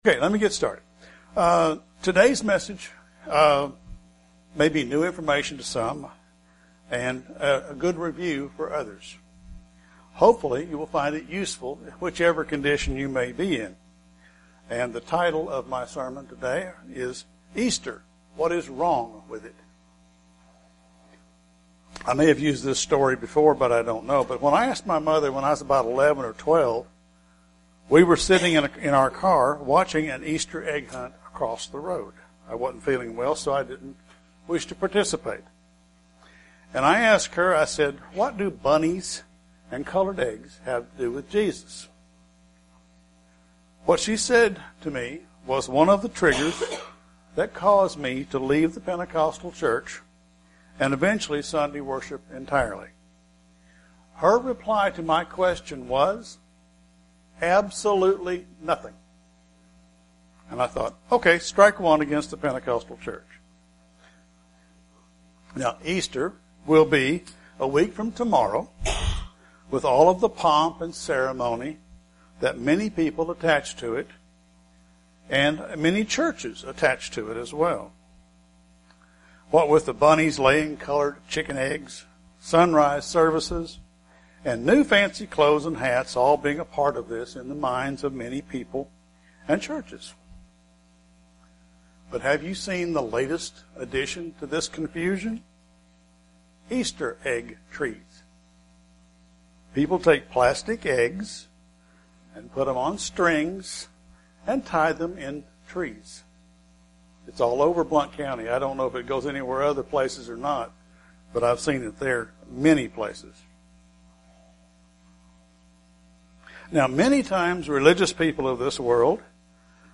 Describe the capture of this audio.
Given in Kingsport, TN Knoxville, TN London, KY